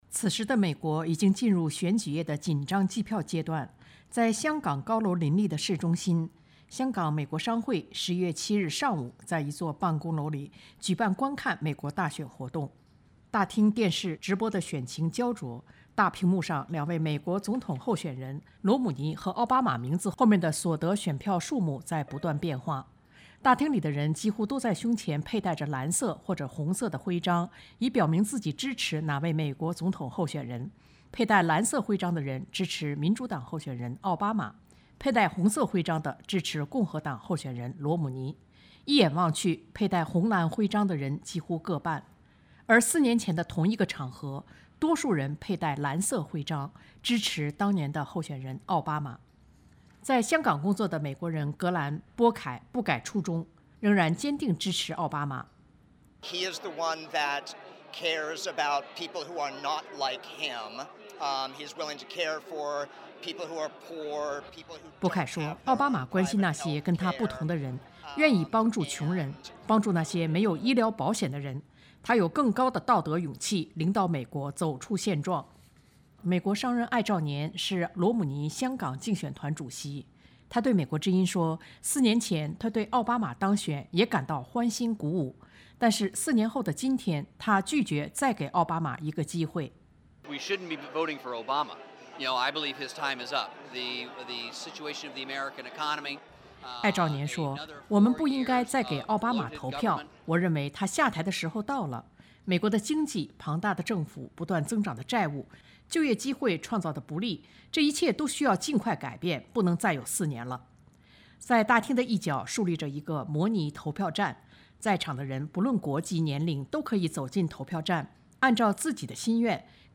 大厅里人声鼎沸，肤色不同、国籍各异的人聚在电视机前，神色专注地观看CNN正在直播的美国大选各州点票结果。这里不是美国，而是在地球另一侧的香港。